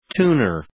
Προφορά
{‘tu:nər}